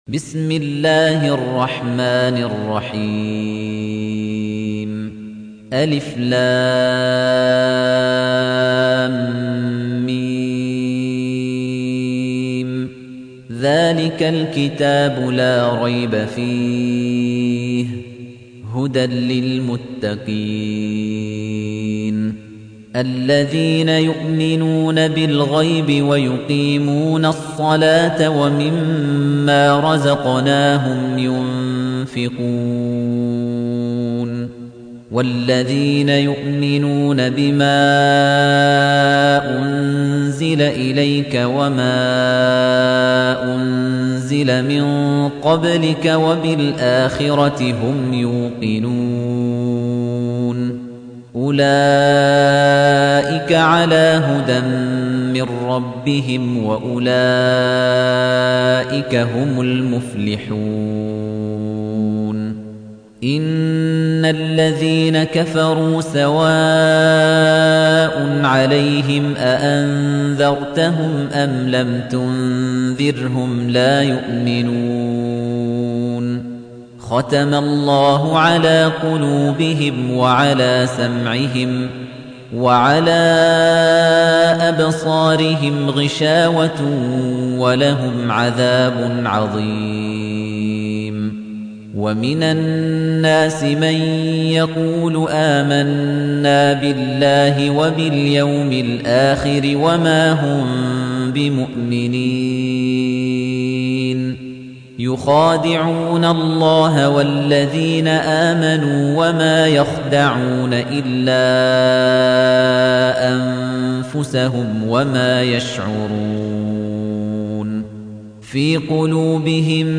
تحميل : 2. سورة البقرة / القارئ خليفة الطنيجي / القرآن الكريم / موقع يا حسين